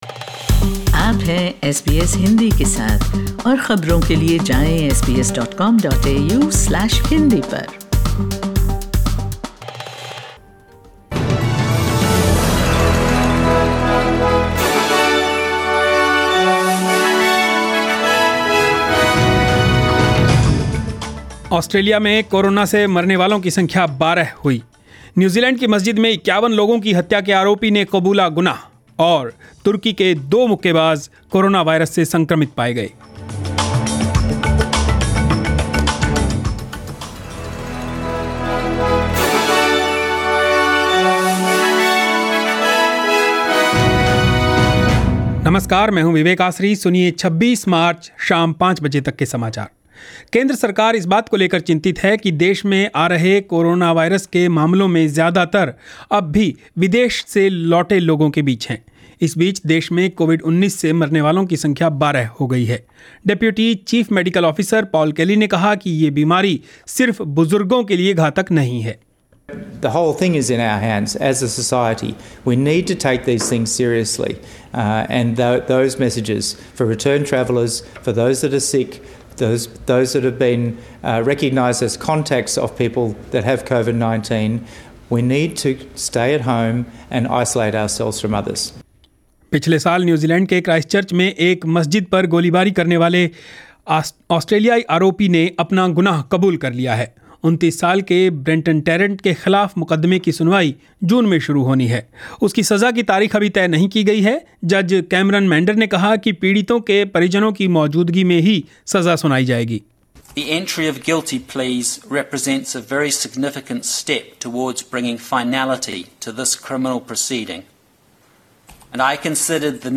News in Hindi 26 March 2020